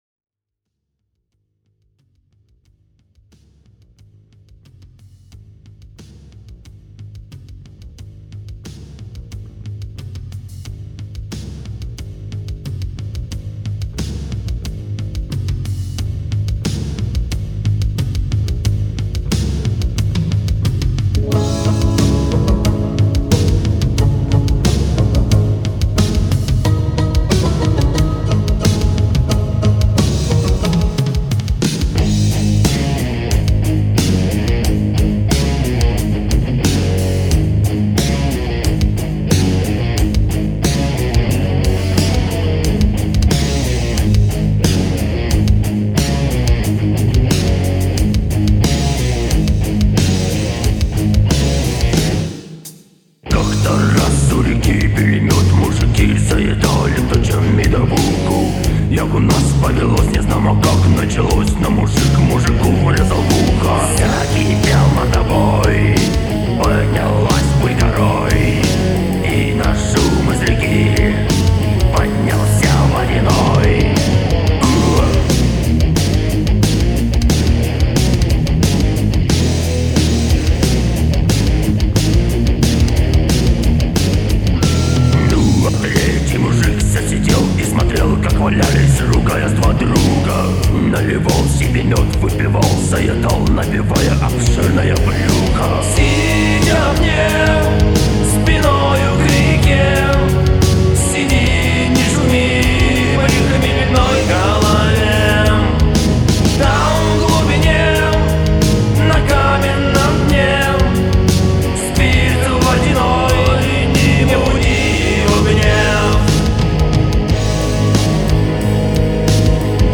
Metal music [29]